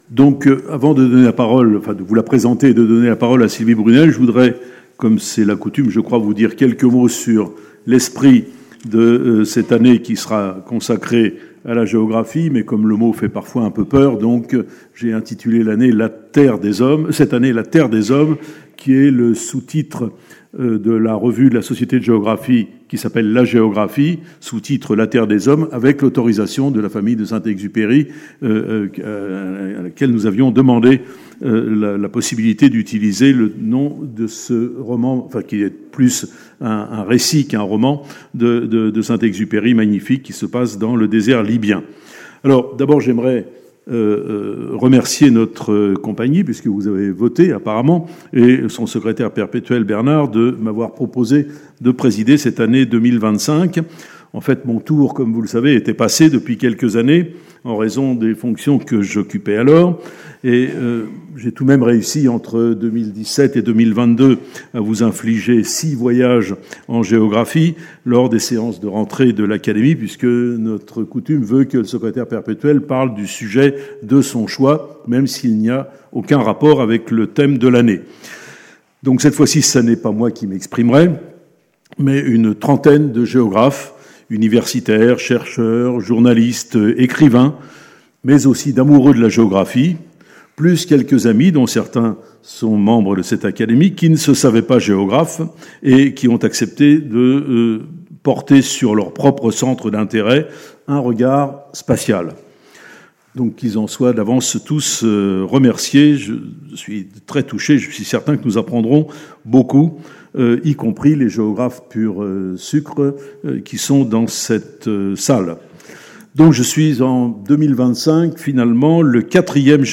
Sylvie Brunel a répondu aux observations et aux questions